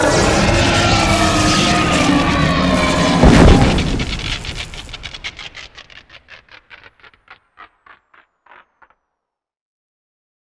TheExperienceLight / sounds / Cosmic Rage / general / combat / ENEMY / droid / bigdie1.wav
bigdie1.wav